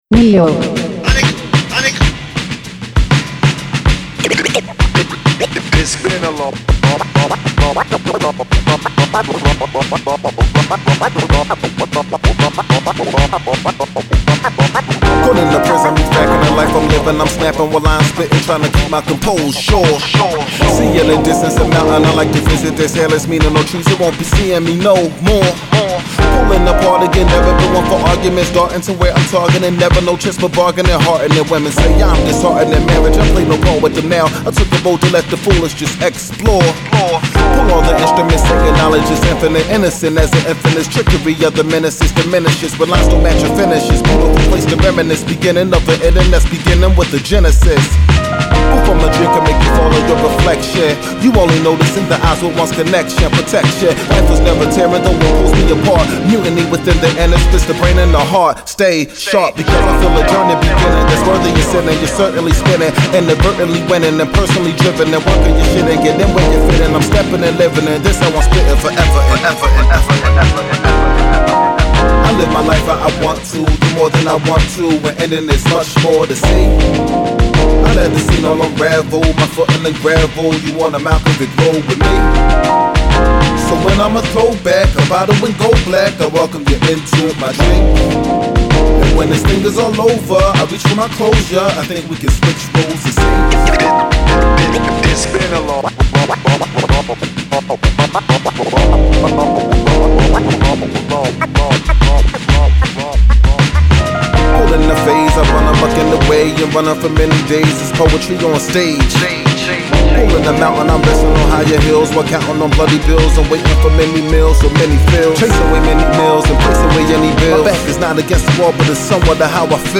hip hop duo